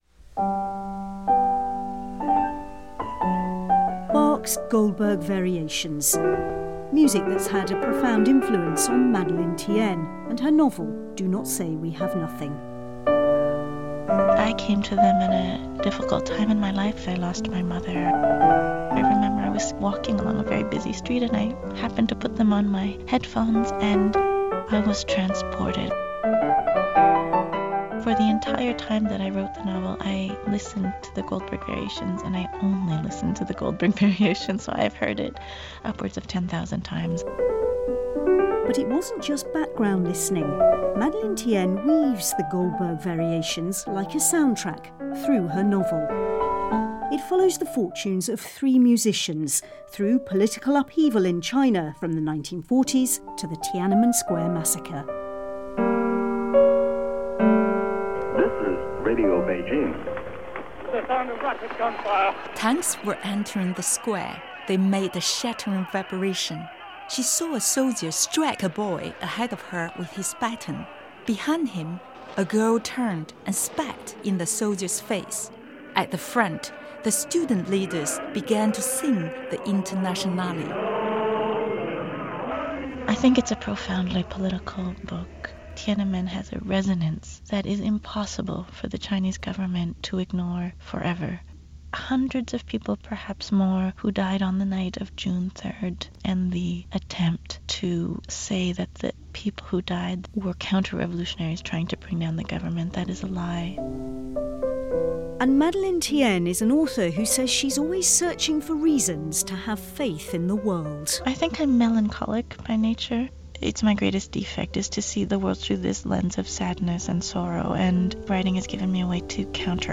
reports for the Today programme on BBC Radio 4.